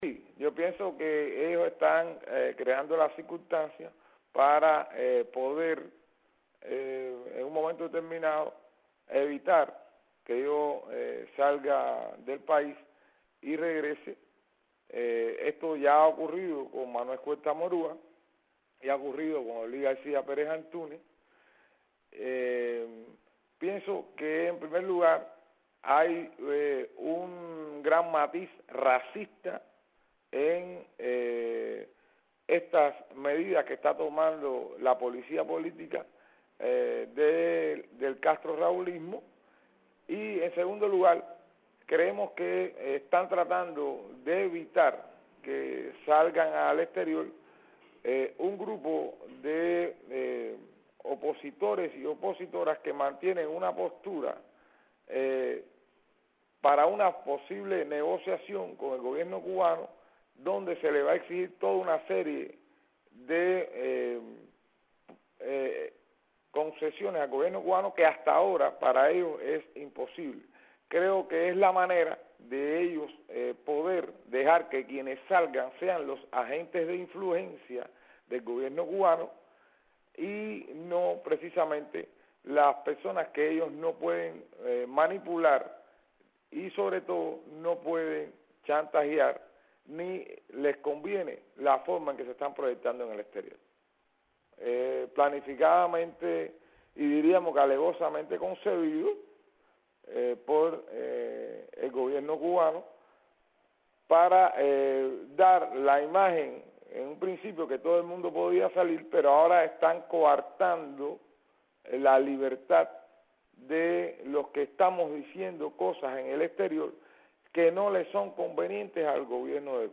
Declaraciones de Guillermo Fariñas sobre aplicacion de medidas cautelares a opositores